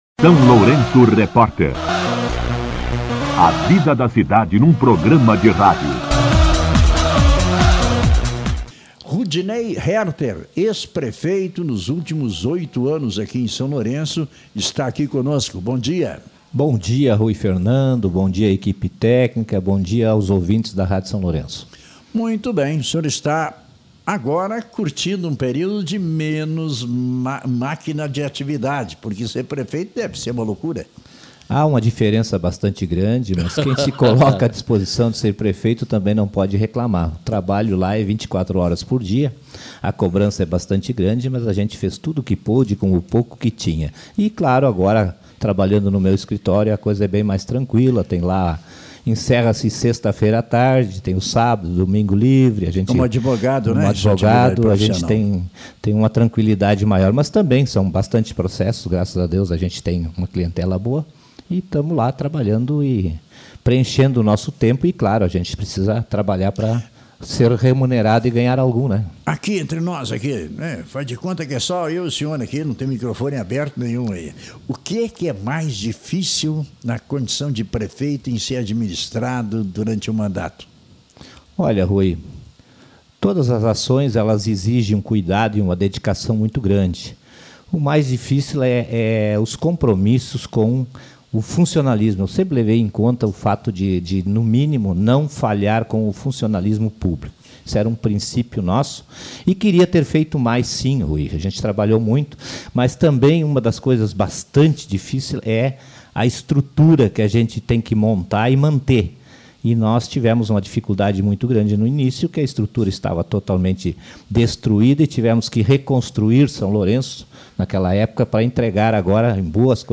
Entrevista com o ex-prefeito Rudinei Härter